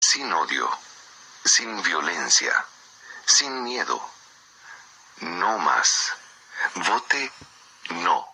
“Sin odio, sin violencia, sin miedo, no más. Vote NO”, decía la clásica voz en off de la recordada campaña de 1988, que acompañaba una secuencia en imágenes en que una mano rayaba la opción NO en el proceso electoral que determinó el término del mandato del general Augusto Pinochet en Chile.
audio-campana-del-no-online-audio-converter-mp3cut-net_.mp3